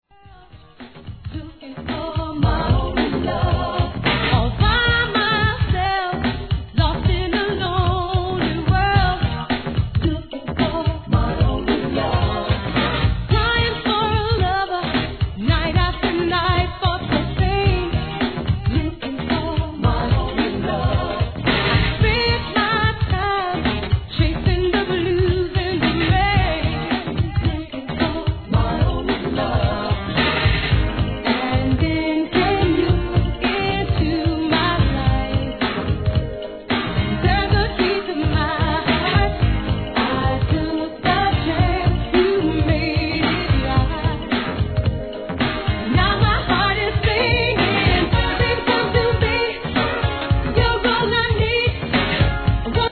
1. HIP HOP/R&B
N.J.S. classic!!